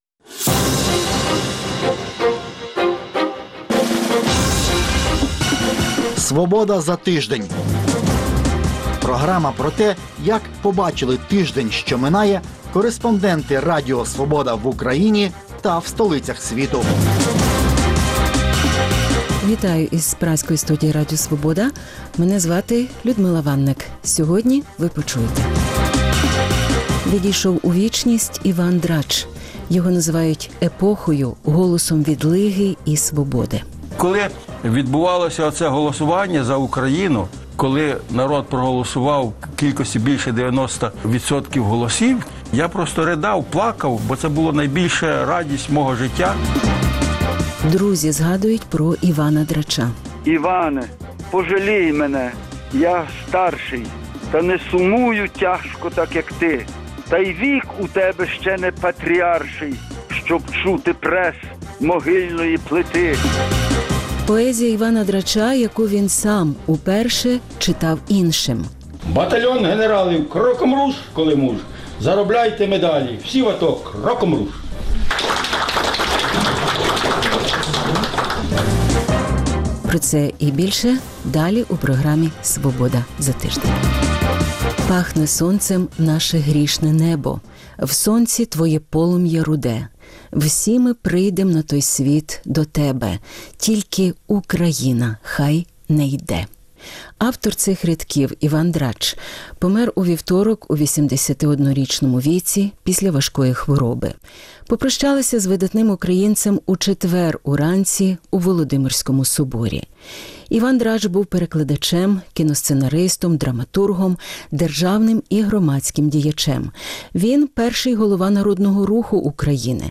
Архівні записи, спогади про Поета. Репортаж із околиць Горлівки. Діти з Донбасу на відпочинку в Німеччині.